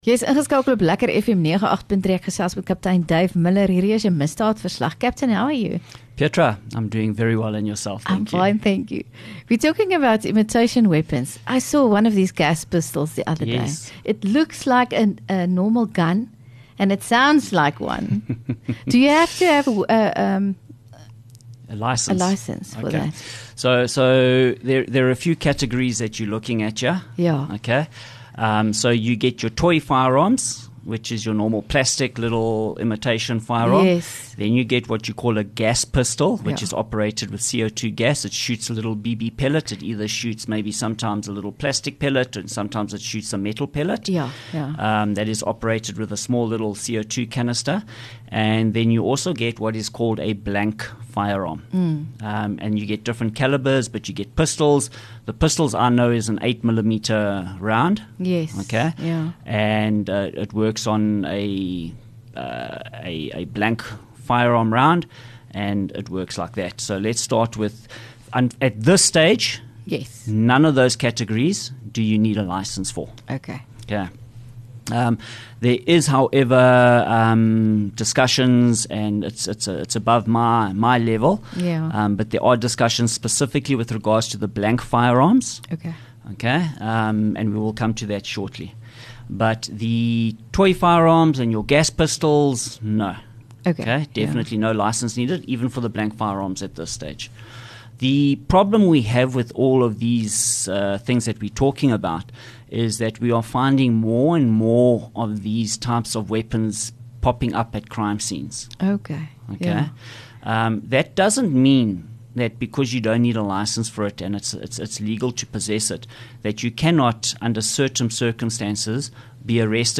LEKKER FM | Onderhoude 8 Aug Misdaadverslag